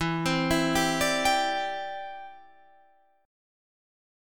Em7#5 chord